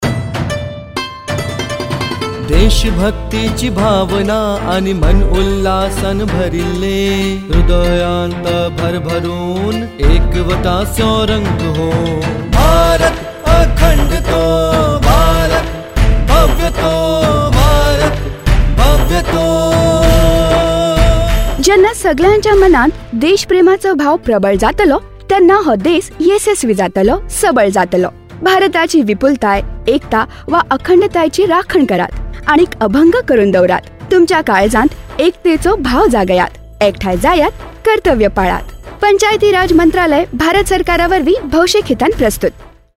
104 Fundamental Duty 3rd Fundamental Duty Protect sovereignty & integrity of India Radio Jingle Konkani